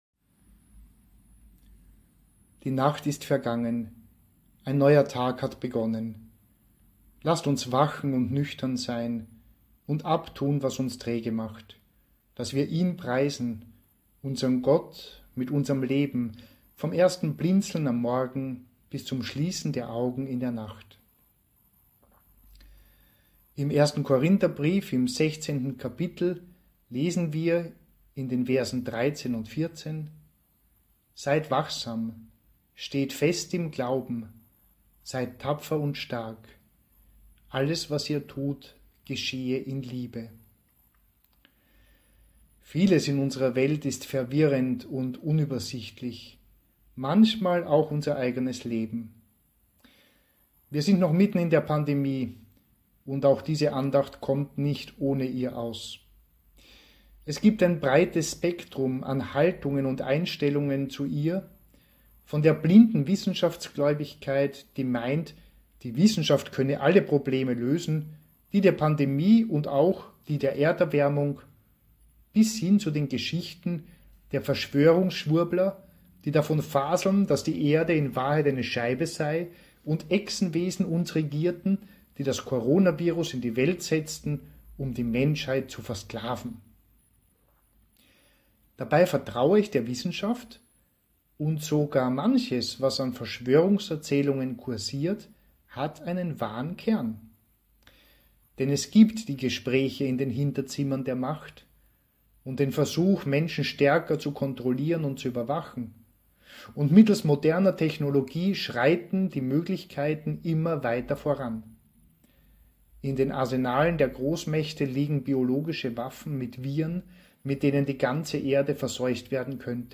Seit den sehr restriktiven Maßnahmen zur Eindämmung der Covid-19 Pandemie haben wir Audio-Minutenandachten gestaltet und aufgenommen.
Minutenandacht „Seid wachsam!“ vom 26.